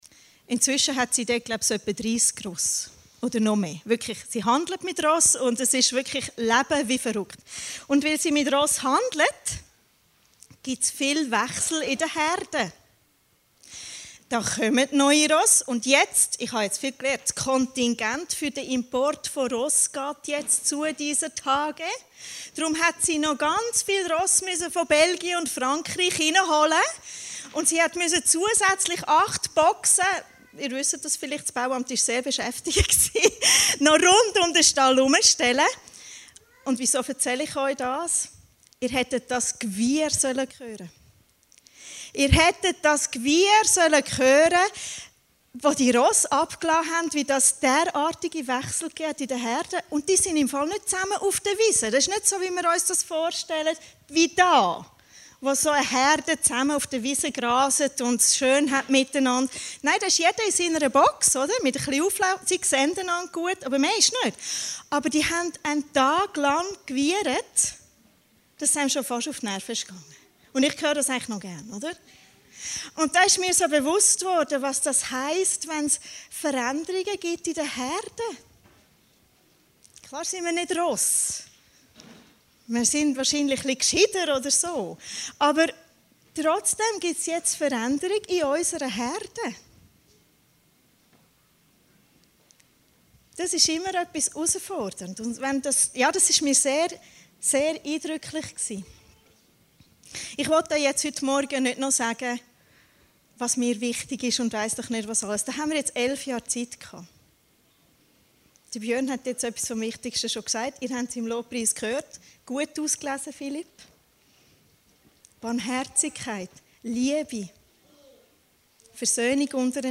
Predigten Heilsarmee Aargau Süd – Hoffnung und Geduld